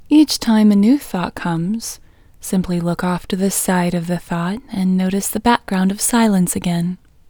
WHOLENESS English Female 4